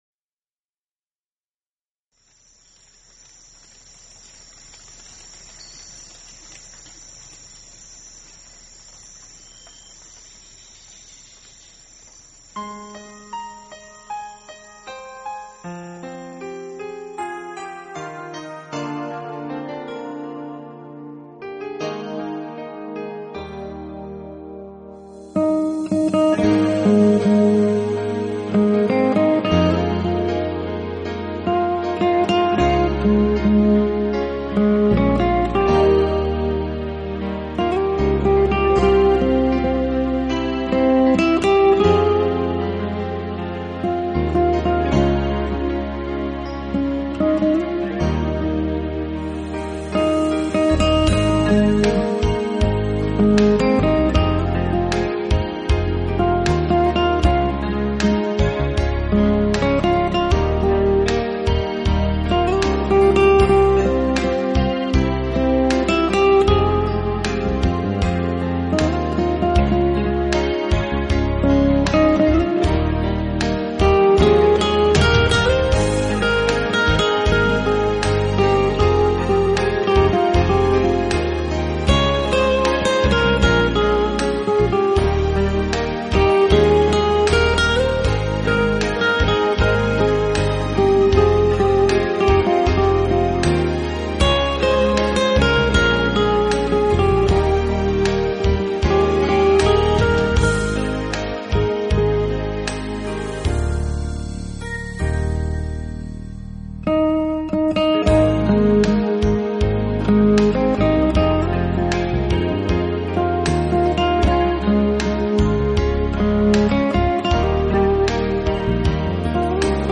简单而清新的吉他声，Bossa Nova的慵懒节奏，